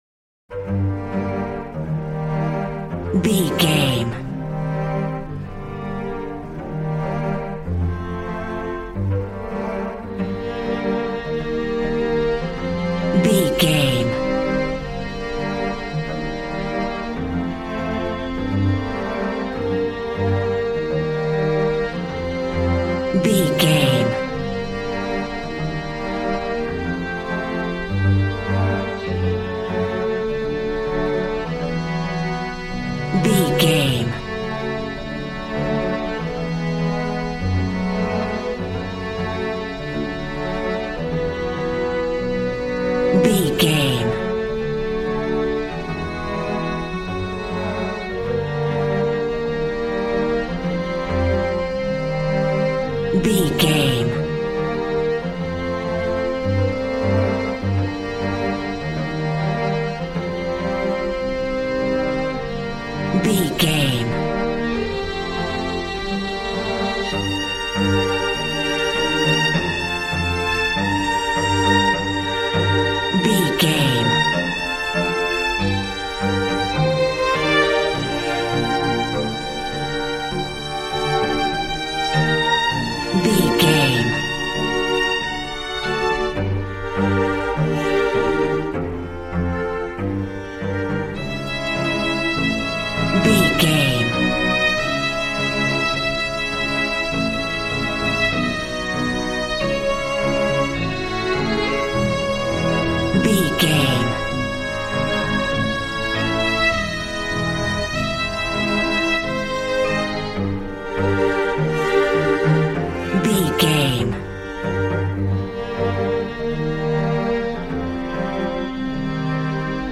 Beautiful stunning solo string arrangements.
Regal and romantic, a classy piece of classical music.
Aeolian/Minor
regal
strings
brass